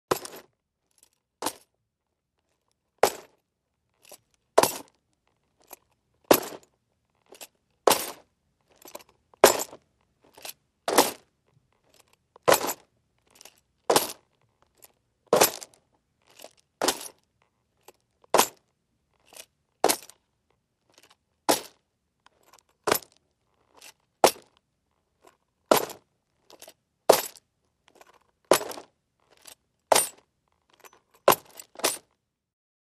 BootsOnGravel PE770201
FOLEY FOOTSTEPS BOOTS: EXT: Slow western walk on gravel with spurs.